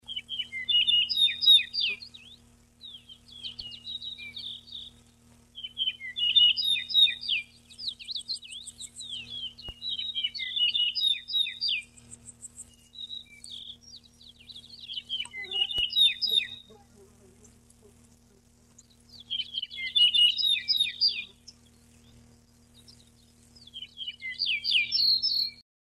Дубровник (Emberiza aureola)
Yellow-breasted Bunting (англ.)